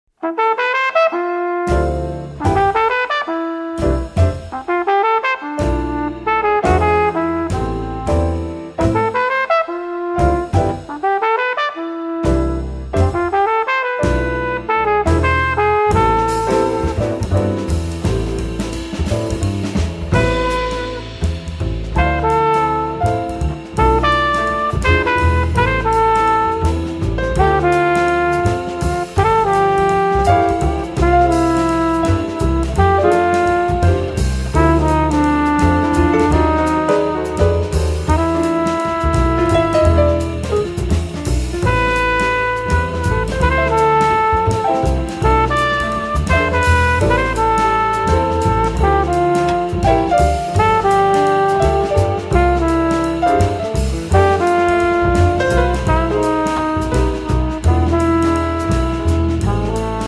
tromba e flicorno
piano
contrabbasso